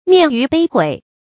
面諛背毀 注音： ㄇㄧㄢˋ ㄧㄩˊ ㄅㄟˋ ㄏㄨㄟˇ 讀音讀法： 意思解釋： 見「面譽背毀」。